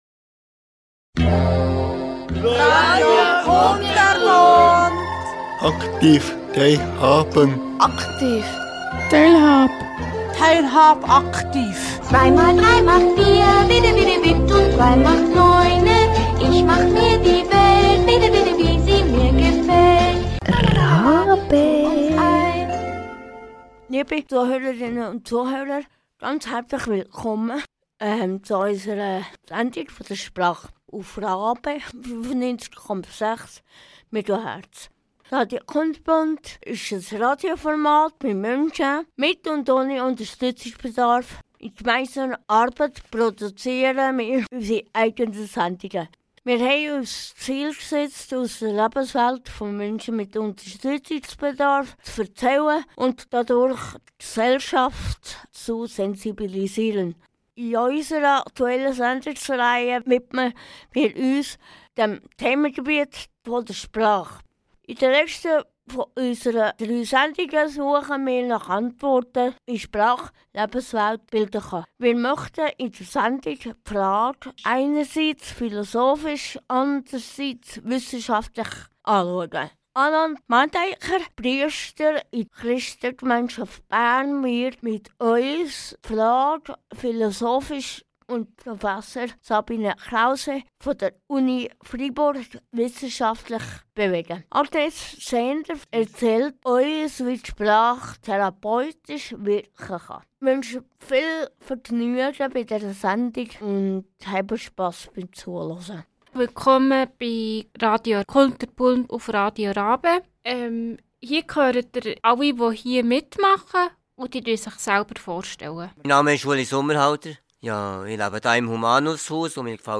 In einem runden Tisch bewegen wir das Thema zum Schluss noch mit den Sendungsmachenden.